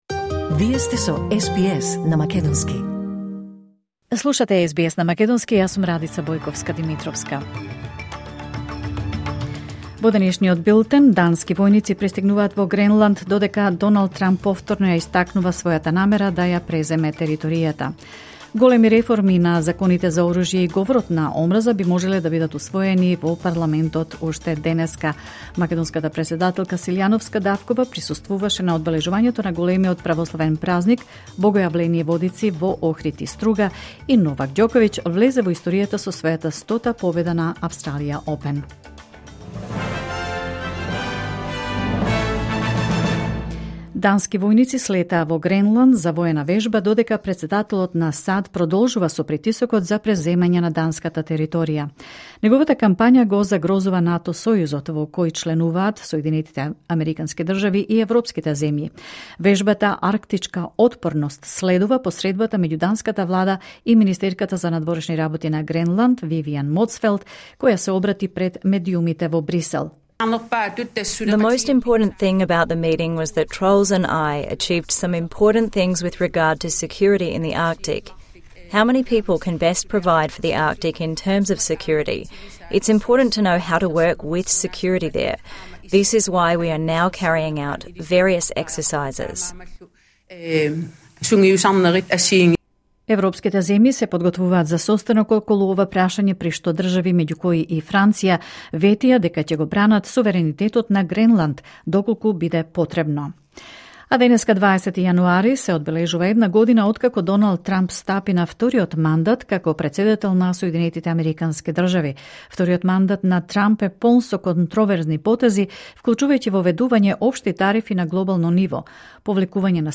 Вести на СБС на македонски 20 јануари 2026